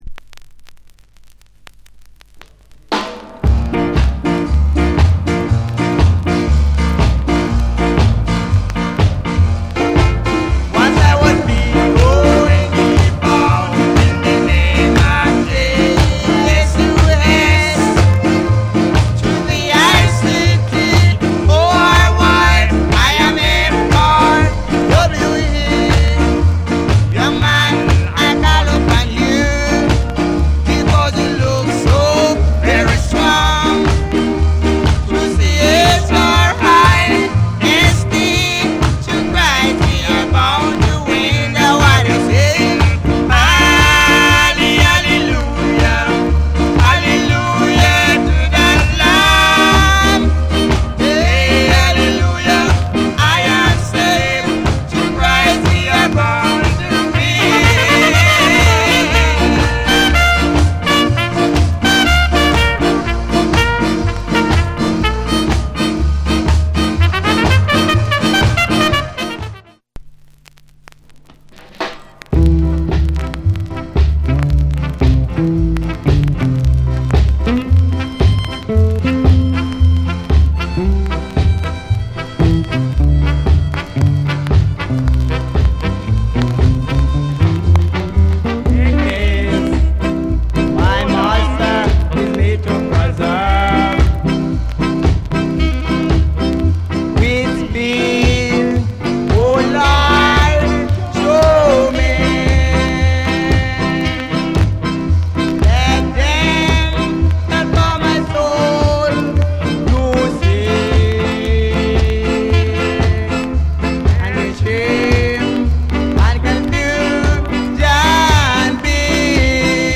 Ska / Male Vocal